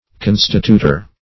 Search Result for " constituter" : The Collaborative International Dictionary of English v.0.48: Constituter \Con"sti*tu`ter\ (k[o^]n"st[i^]*t[=u]`t[~e]r), n. One who constitutes or appoints.